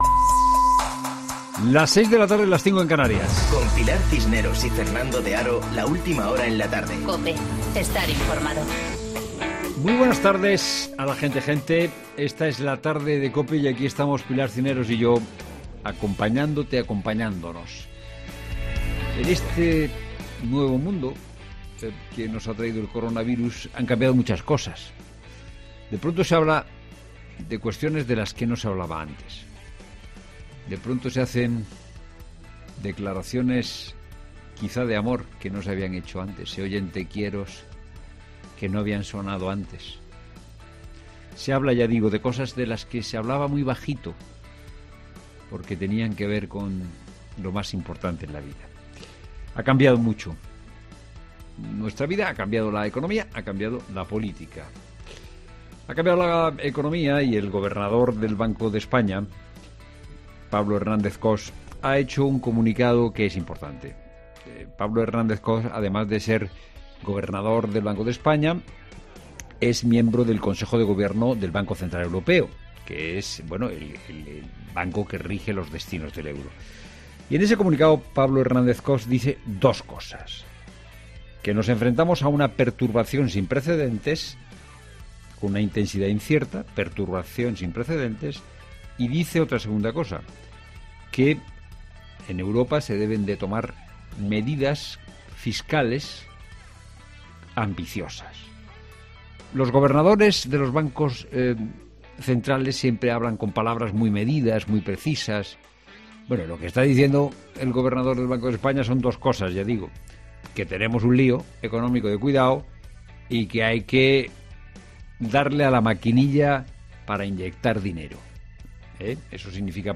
Boletín de noticias COPE del 20 de marzo de 2020 a las 18.00 horas